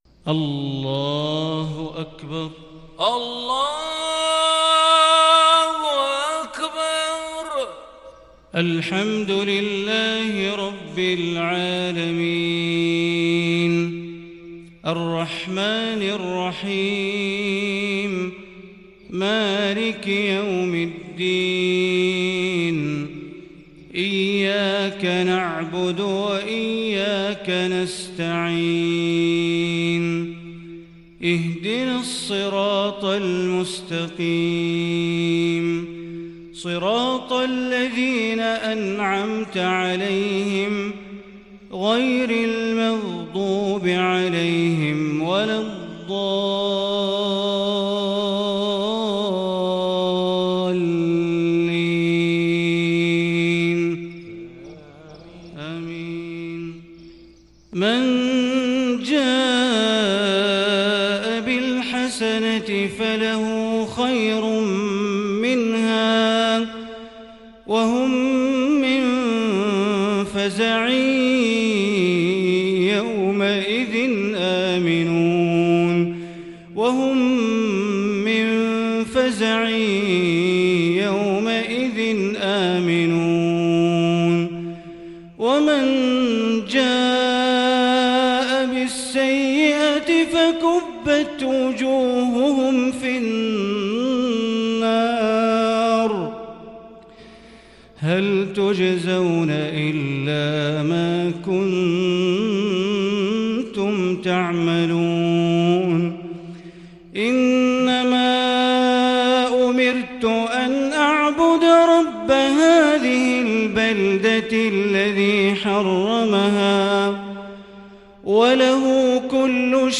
صلاة العشاء للشيخ بندر بليلة 17 رجب 1441 هـ
تِلَاوَات الْحَرَمَيْن .